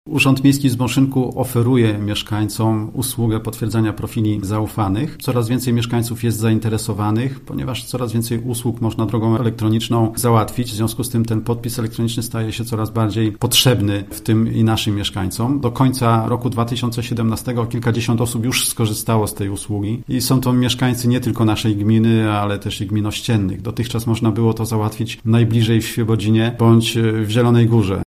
– Coraz więcej spraw można załatwić bez wychodzenia z domu, więc podpis elektroniczny będzie coraz bardziej potrzebny – tłumaczy Jan Makarowicz, zastępca burmistrza Zbąszynka.